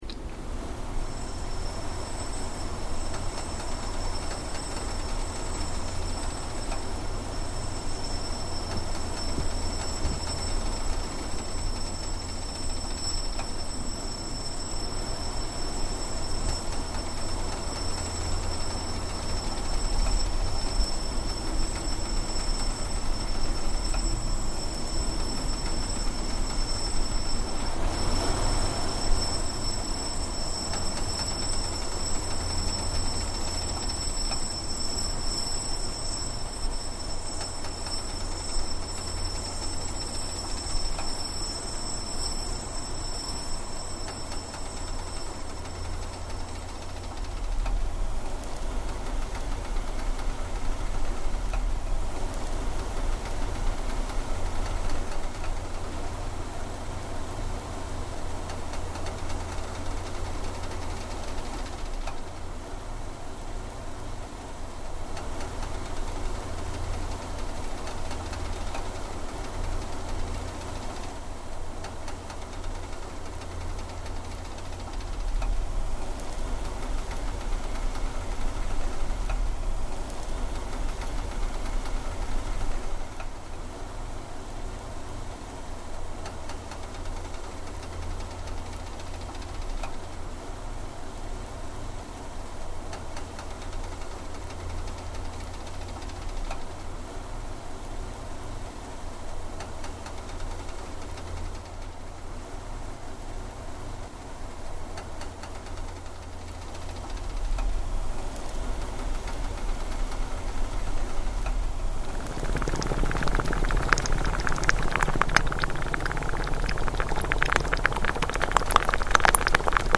Soundscape: Hidden in Plain Site